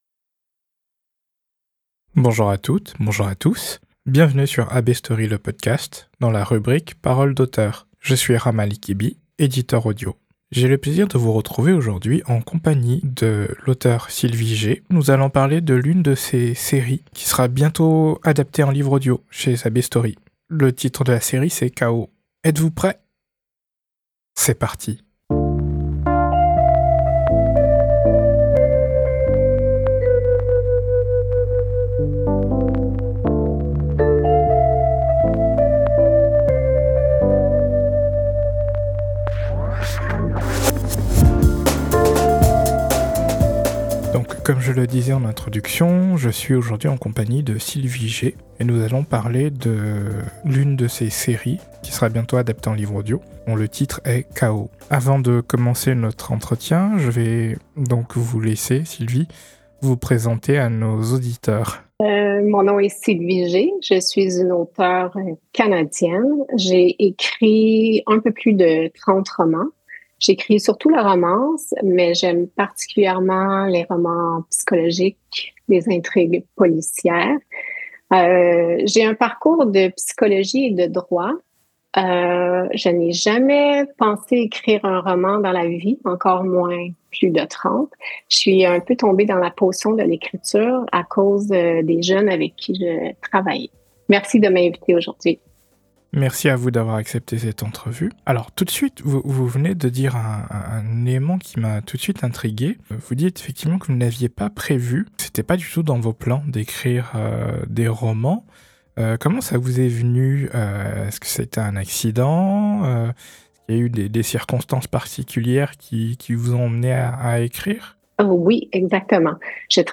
entrevue-chaos.mp3